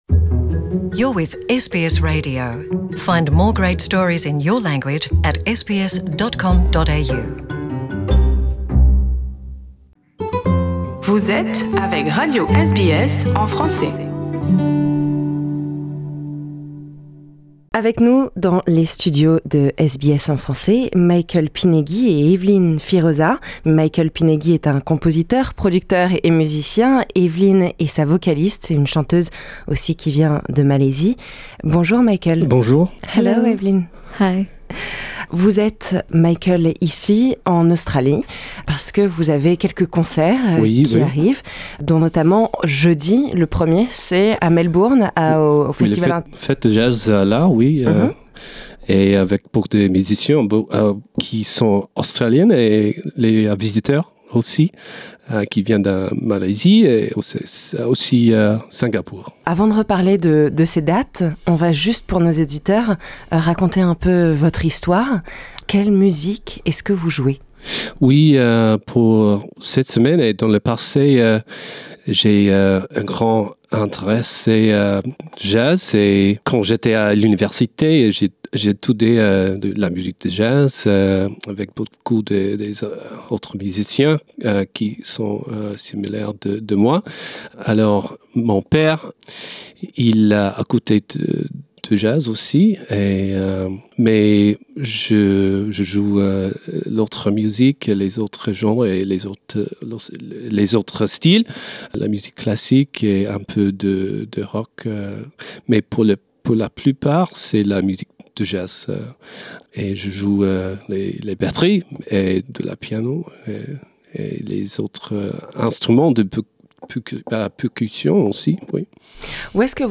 nous présente son style, ses inspirations, sa musique, dans les studios de SBS Radio.